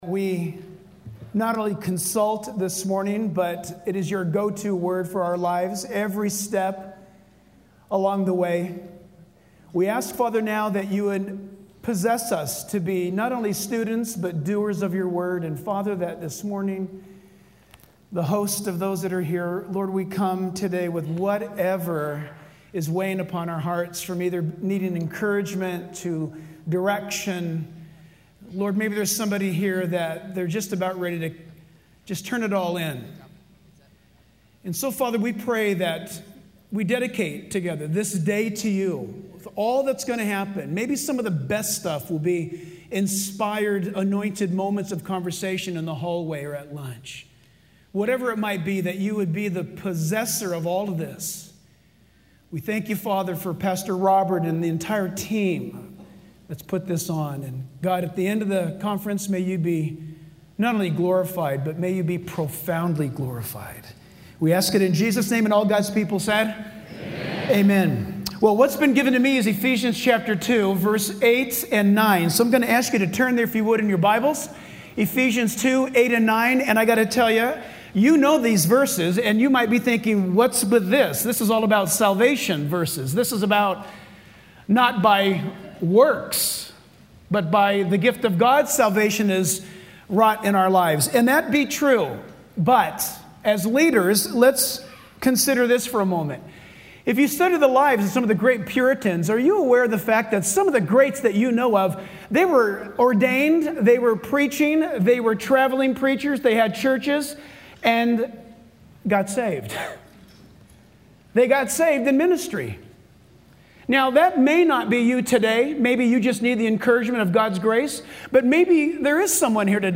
2016 SW Pastors and Leaders Conference